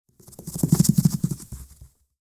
Все записи натуральные и четкие.
Звук: мышь или крыса шуршит по ковровому полу